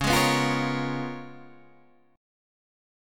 C#13 chord